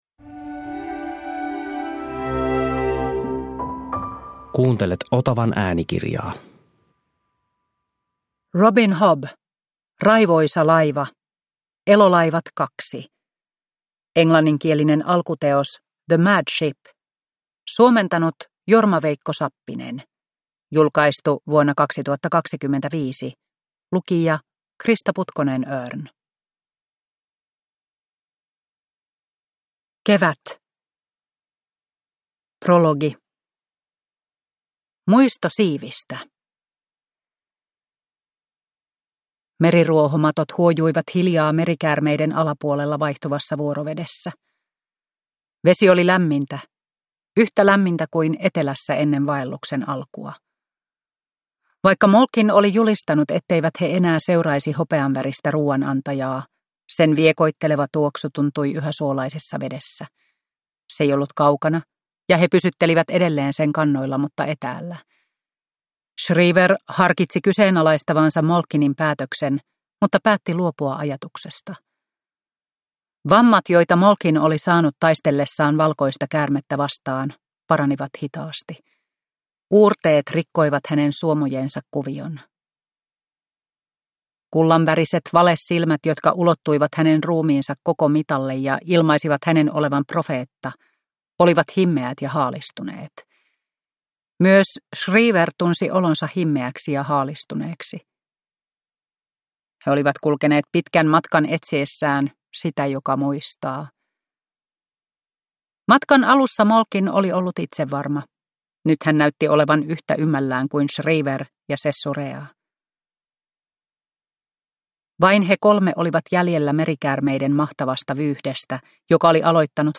Raivoisa laiva – Ljudbok